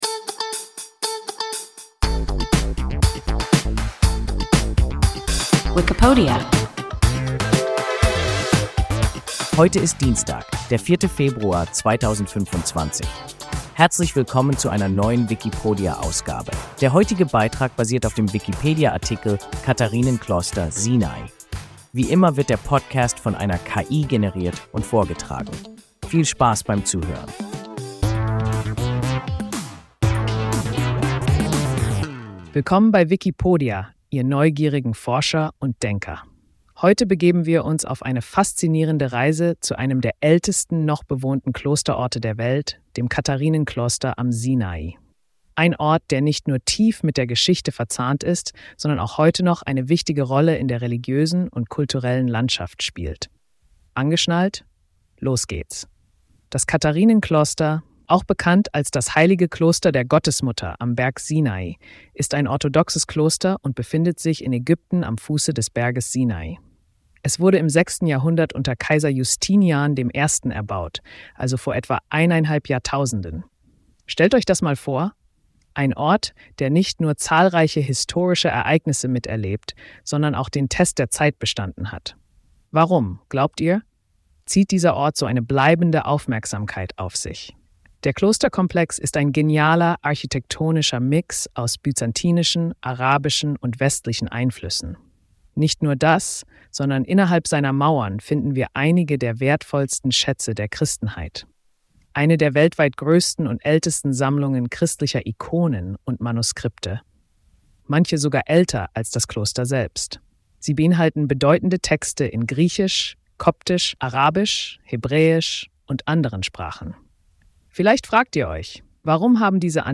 Katharinenkloster (Sinai) – WIKIPODIA – ein KI Podcast